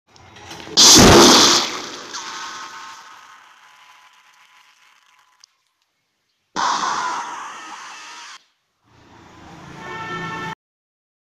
Classic G Major